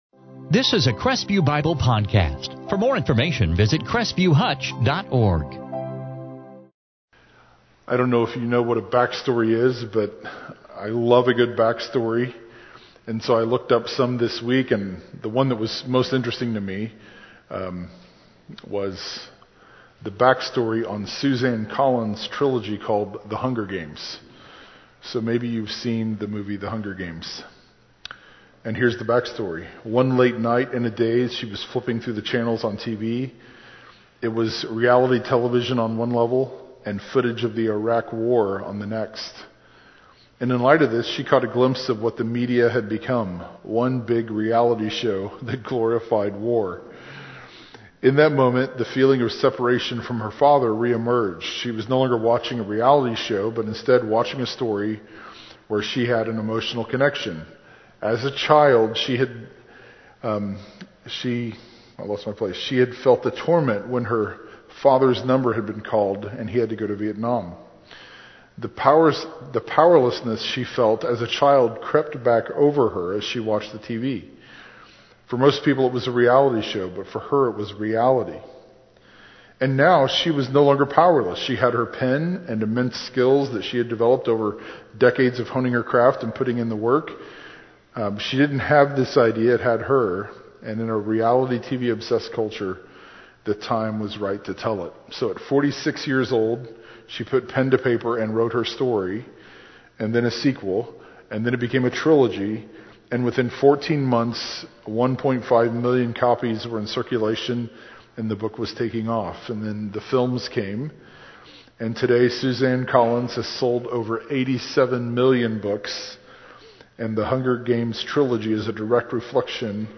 2019 Advent 2019 Matthew Transcript In this sermon from Matthew 1:1-17